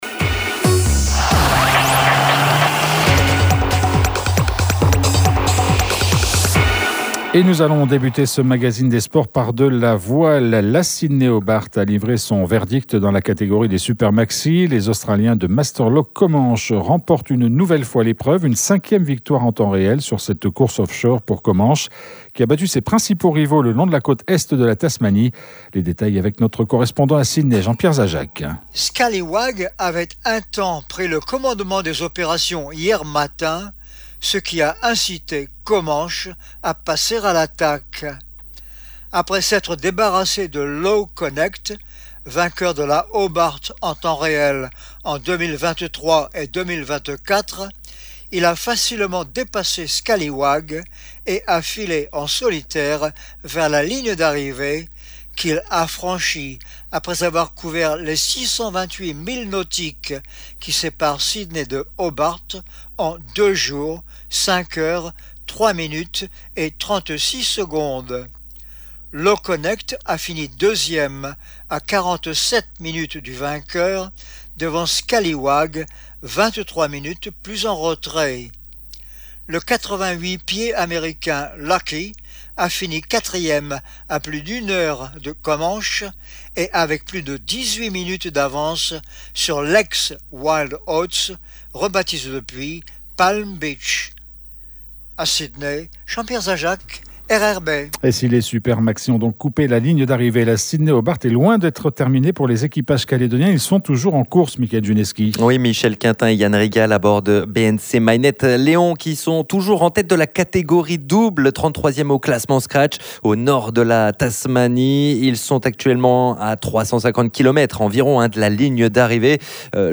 Deux belles semaines à venir pour le tennis. Nous en parlons dans notre Mag’des Sports ce midi, avec nos invités